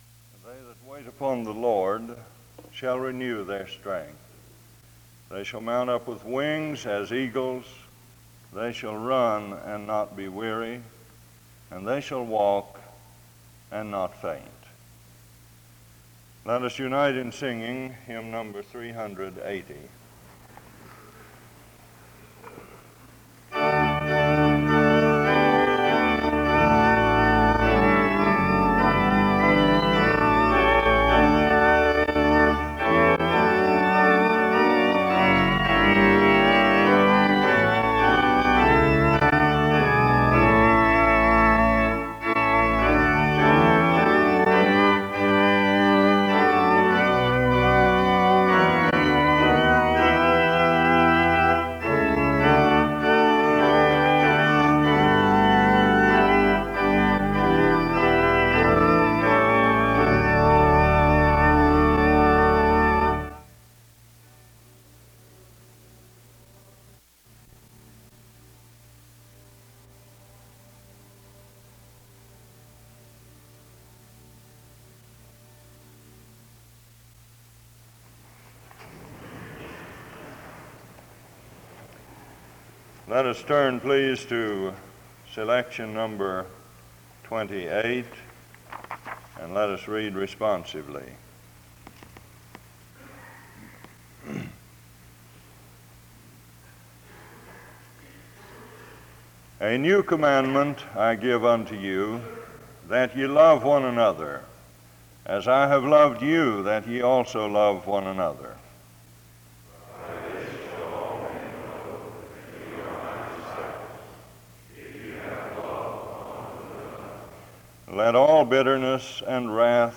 The service begins with an opening scripture reading and song from 0:00-1:03. A responsive reading is read from 1:23-3:38.
A prayer is offered from 3:42-6:38. An introduction to the speaker is given from 6:45-8:58.
Location Wake Forest (N.C.)